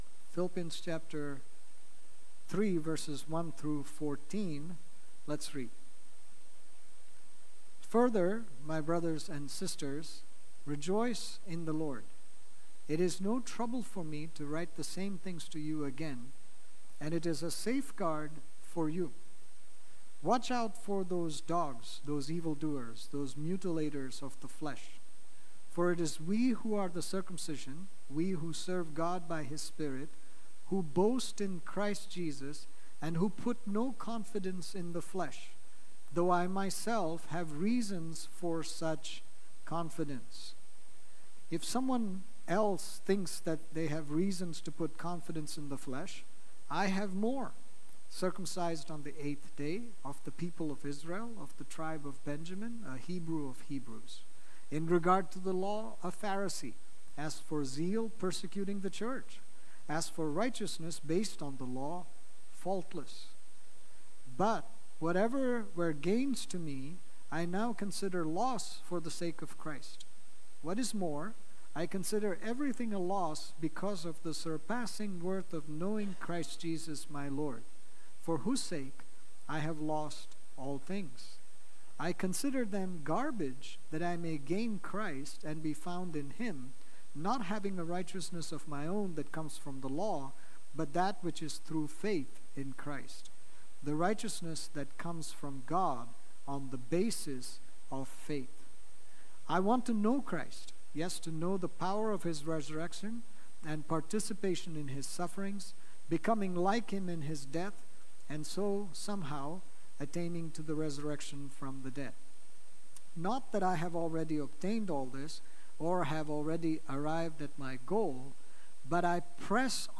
This sermon on Philippians 3:1-14 was delivered